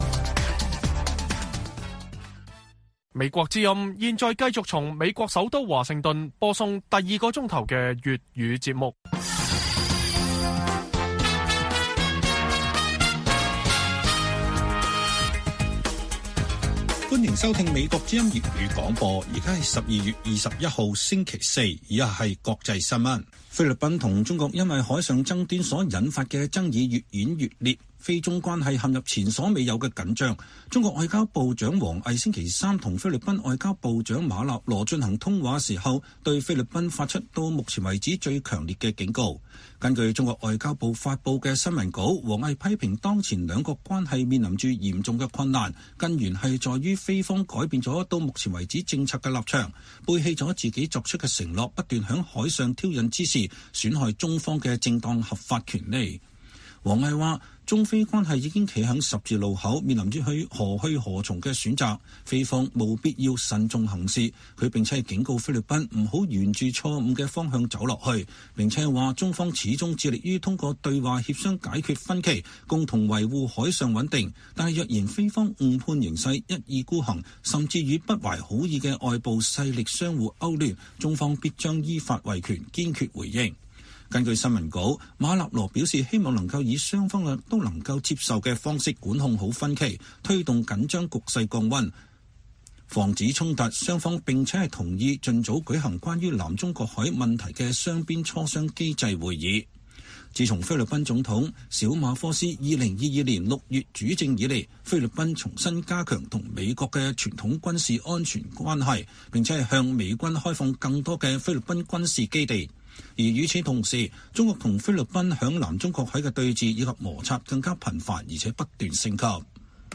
粵語新聞 晚上10-11點 : 中俄軍方舉行第22輪戰略磋商美國及盟友警惕